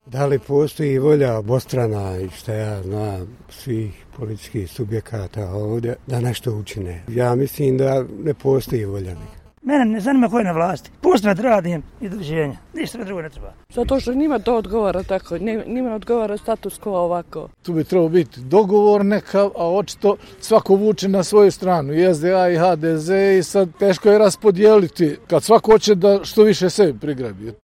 Zašto nije bilo izbora i hoće li ih biti, neki od građana su nam kazali: